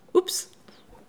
oups_01.wav